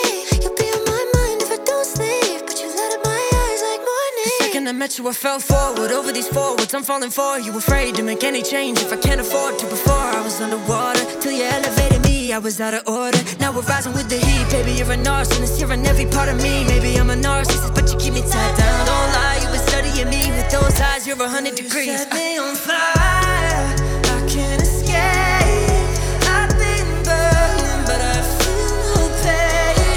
2025-06-15 Жанр: Поп музыка Длительность